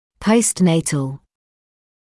[ˌpəust’neɪtl][ˌпоуст’нэйтл]постнатальный, послеродовой